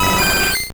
Cri de Leveinard dans Pokémon Or et Argent.